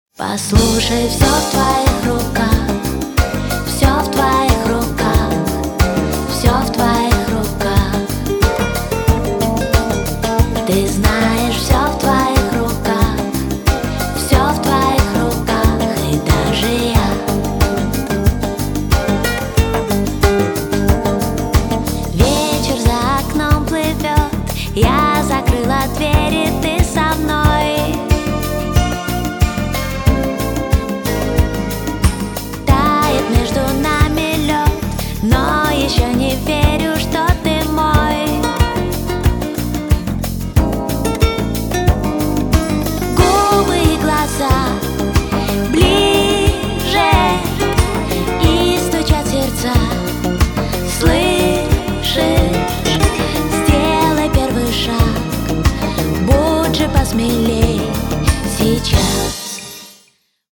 • Качество: 320, Stereo
поп
гитара
красивые
женский вокал